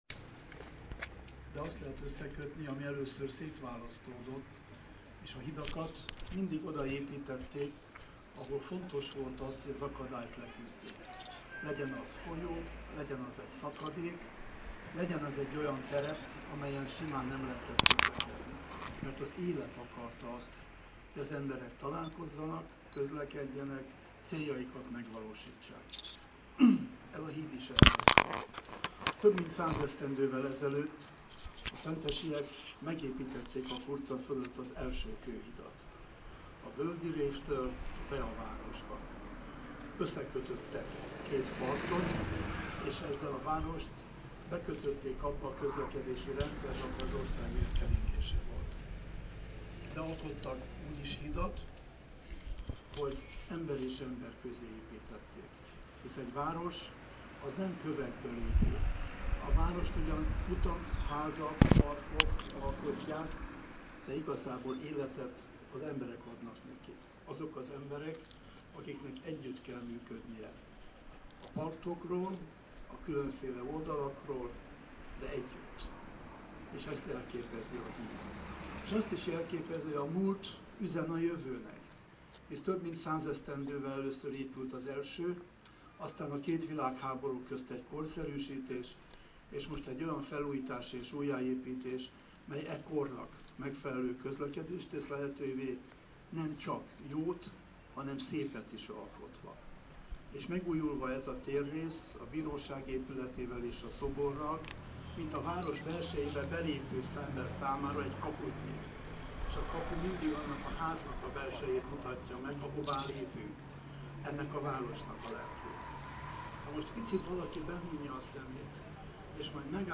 Híd- és szoboravatás a város szívében